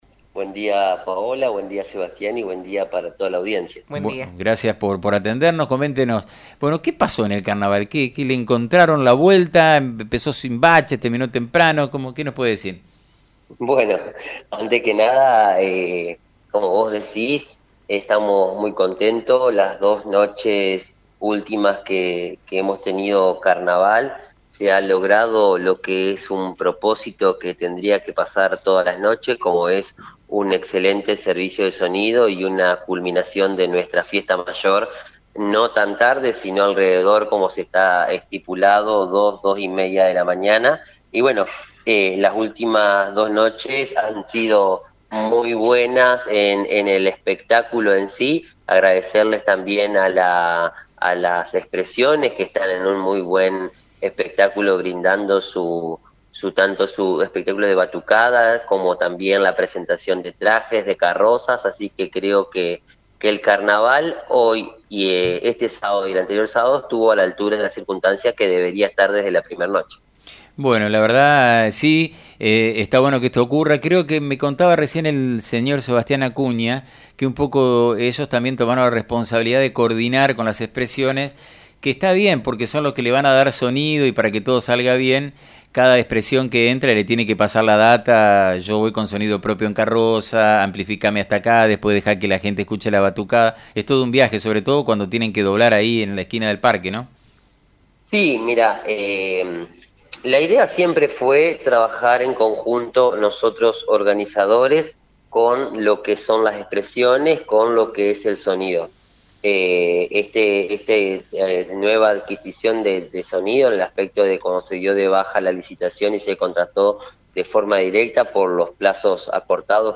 En diálogo con FM 90.3 el Secretario de Gobierno, Pedro Mansilla, aseguró que la Casa de la Mujer se encuentra en la etapa final de su construcción. Por otra parte, está por realizarse la licitación para la instalación eléctrica de la nueva Terminal de Ómnibus.